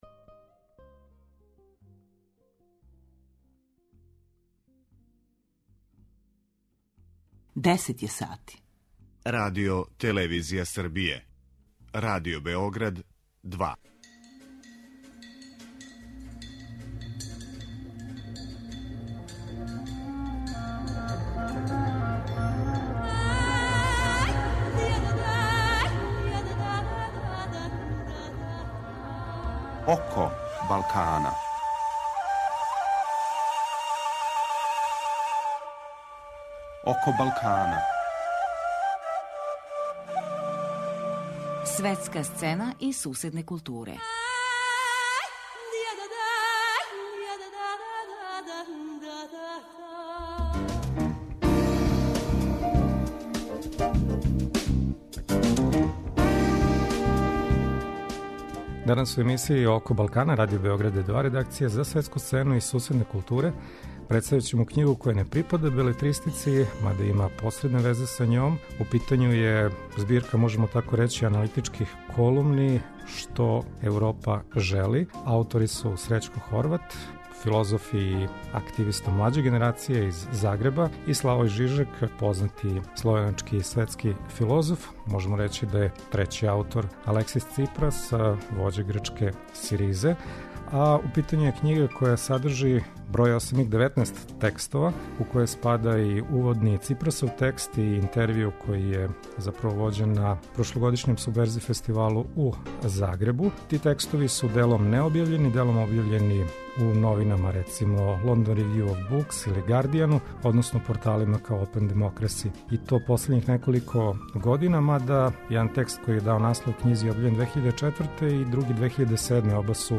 Гост емисије је Срећко Хорват, филозоф и активиста млађе генерације, из Загреба.